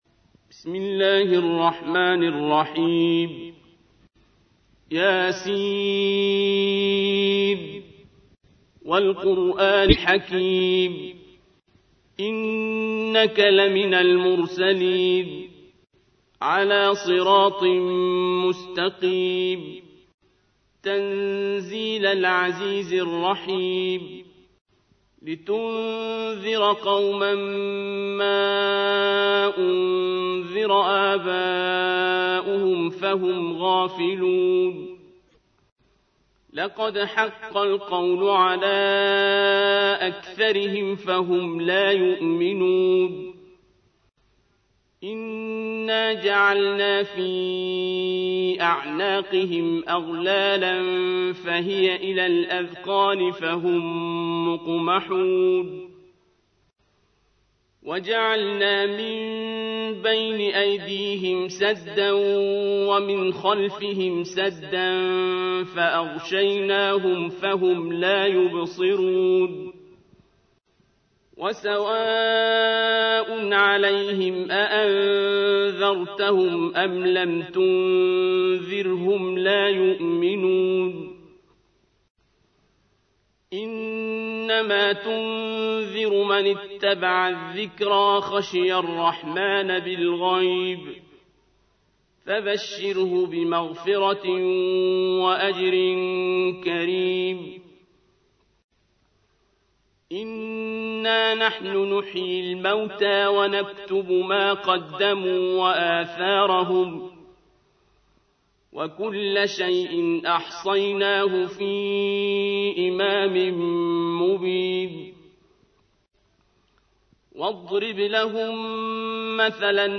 ترتیل سوره مبارکه یس